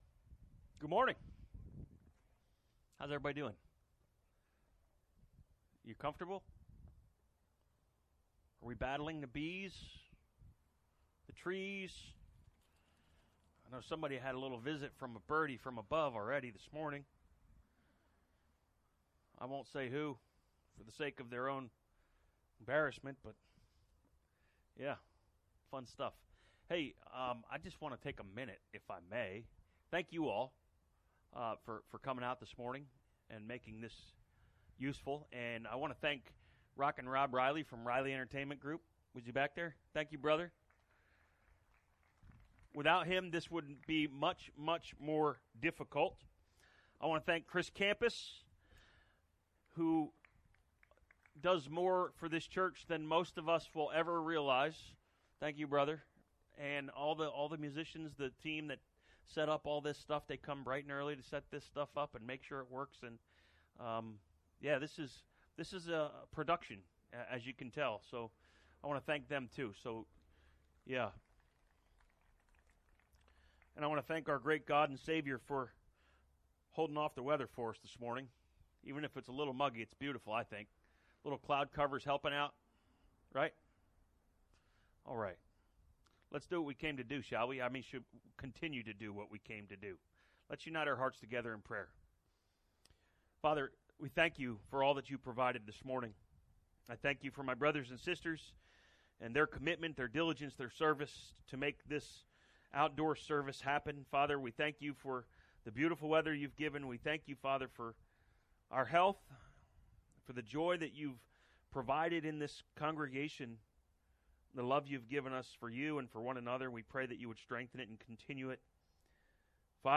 From Series: "2023 Sermons"